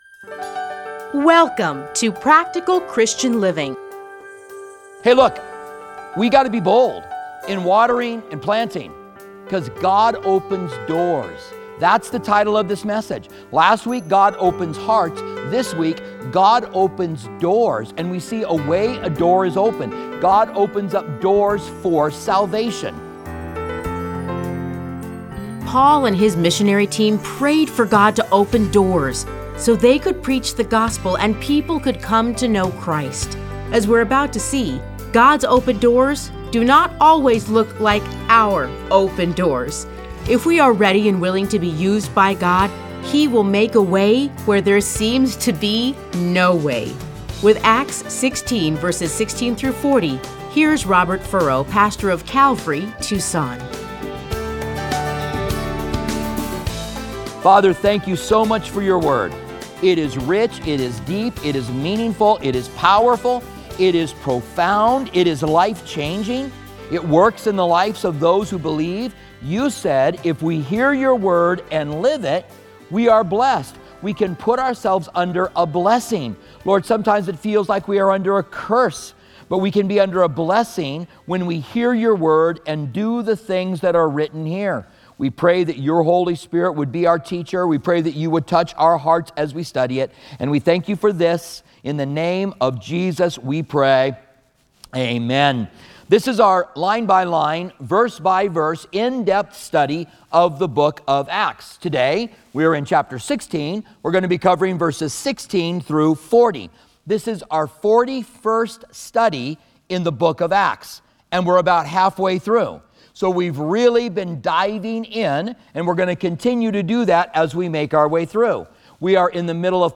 Listen to a teaching from Acts 16:16-40.